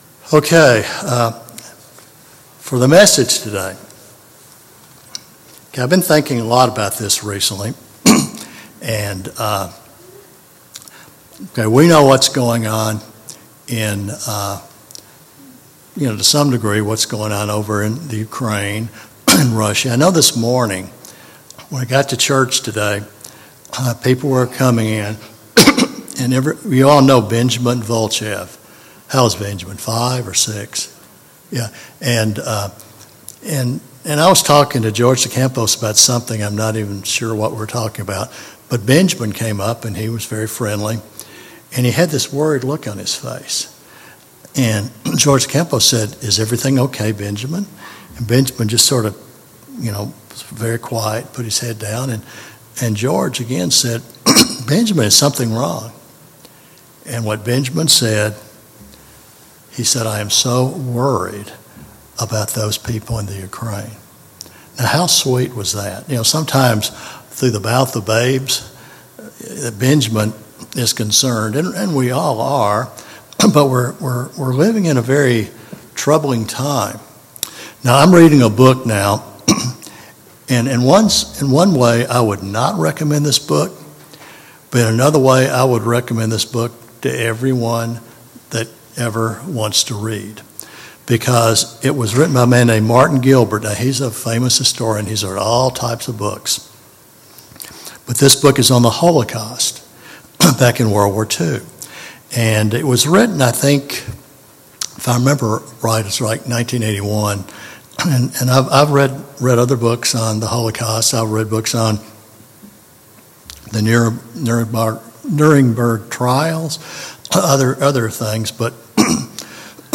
Sermons
Given in Fort Worth, TX